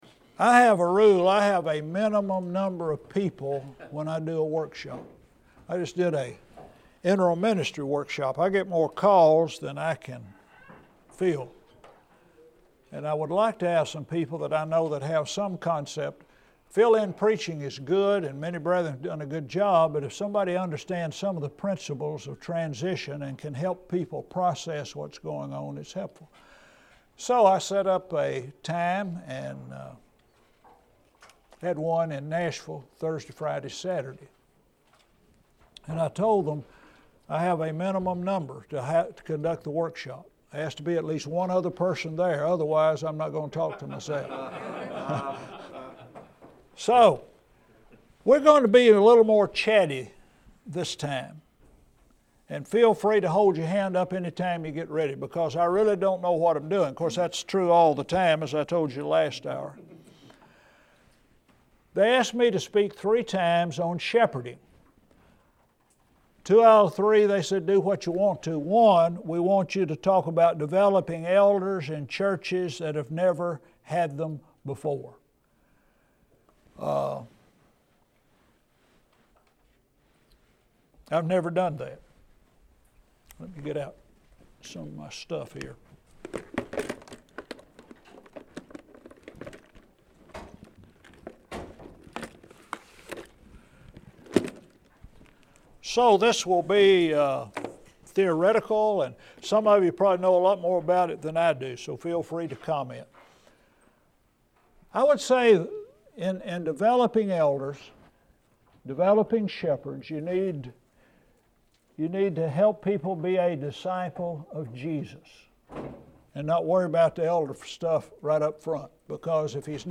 Scholar Works at Harding - Annual Bible Lectureship: Shepherding for Shepherds, Prospective Shepherds, and Sheep, part 2 of 3